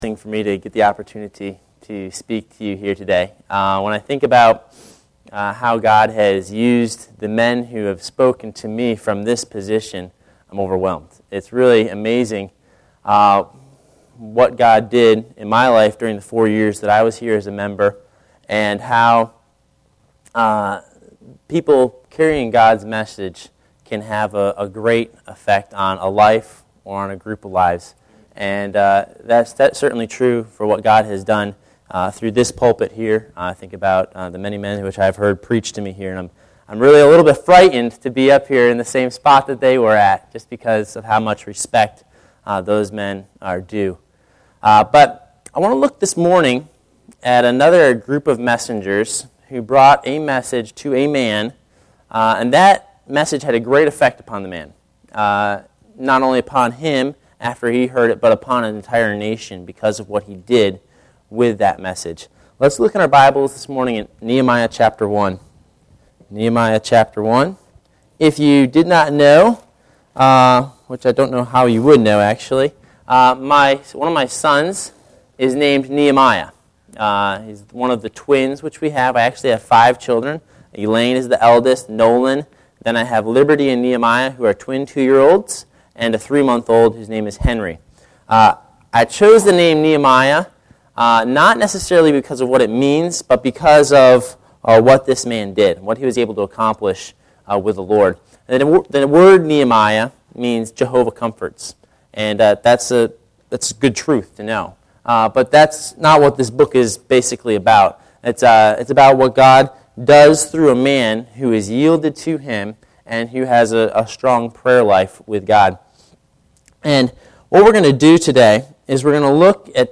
Nehemiah Service Type: Adult Sunday School Class Bible Text